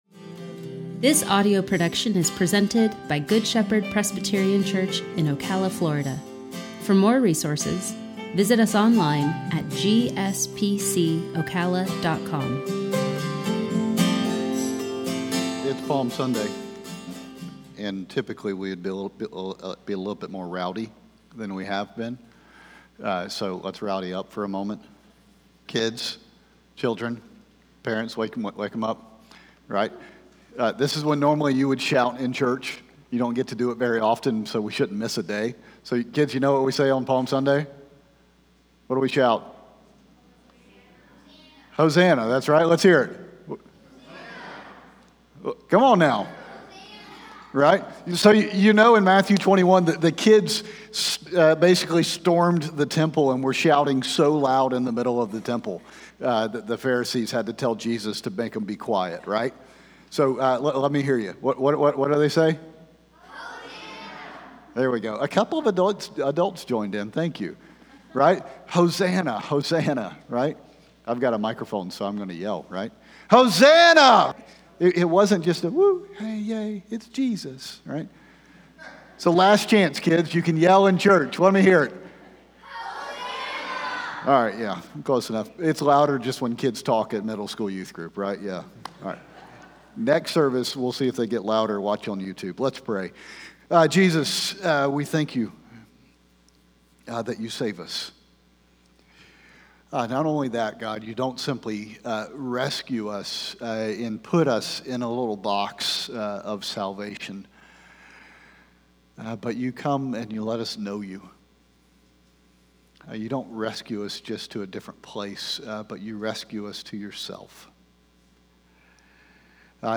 sermon-3-28-21.mp3